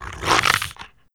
c_goril_atk1.wav